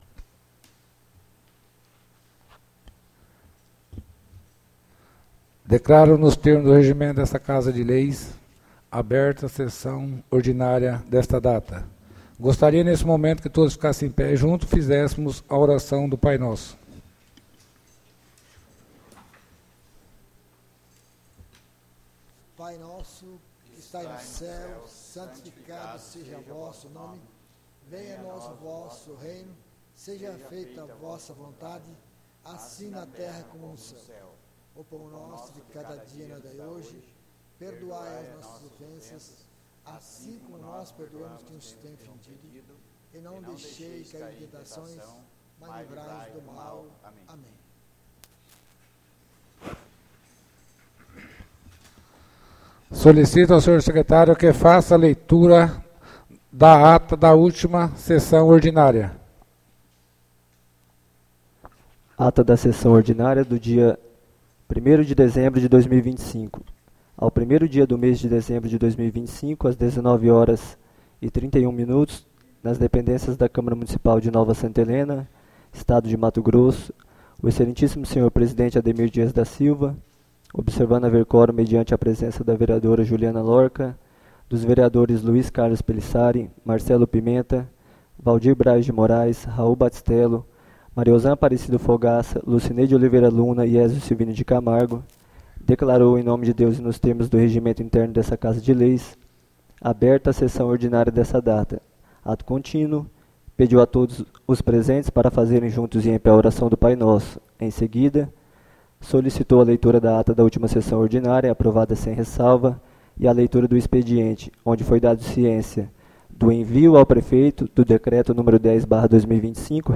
ÁUDIO SESSÃO 08-12-25 — CÂMARA MUNICIPAL DE NOVA SANTA HELENA - MT